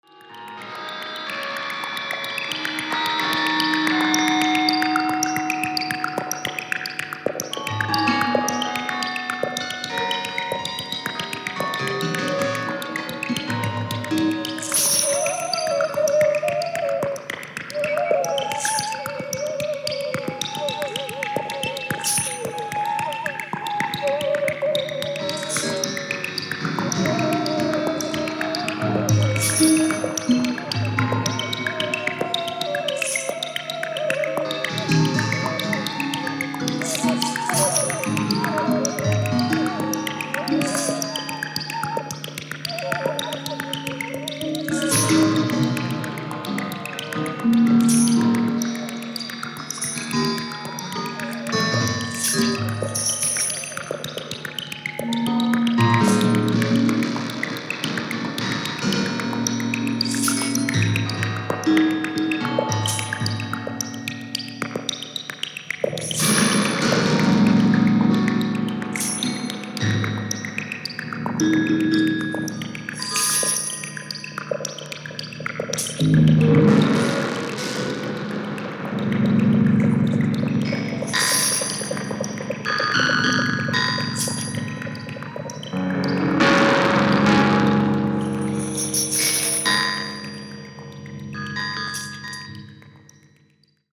Ambient, Experimental